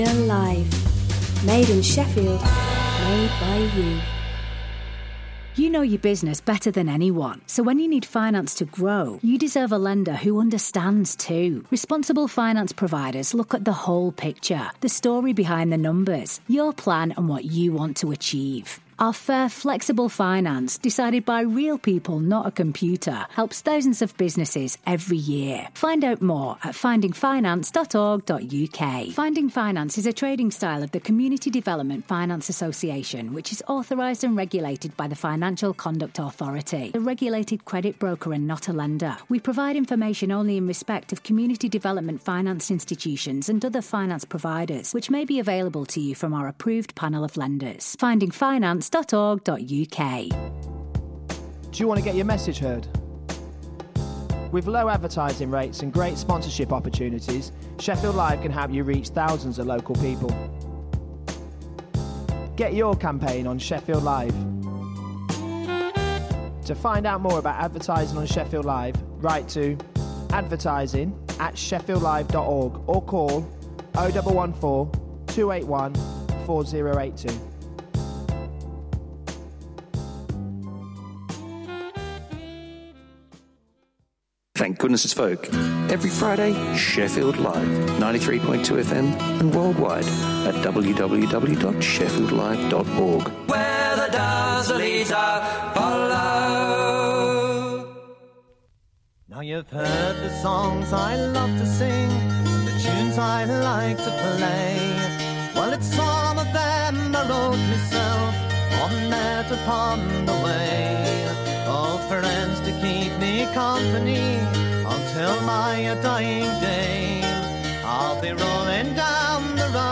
Traditional folk music from the British Isles